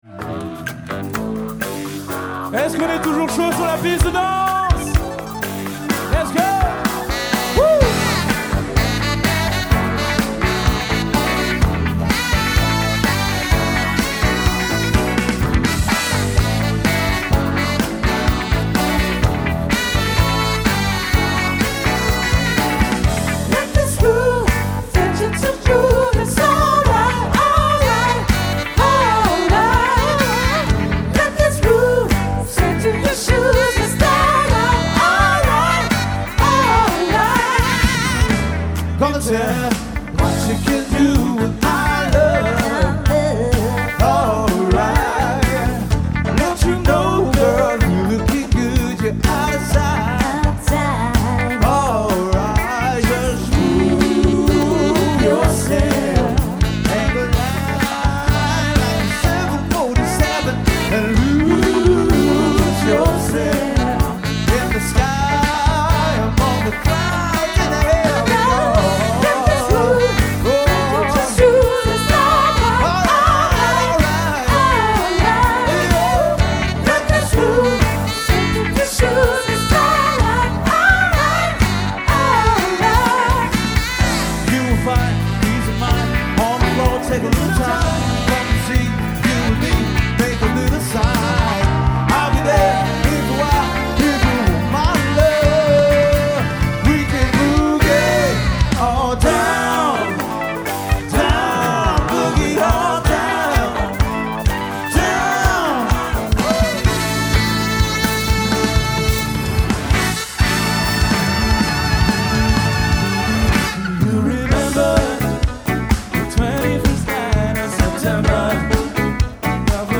Un show sur-mesure jusqu'à 18 musiciens sur scène en live.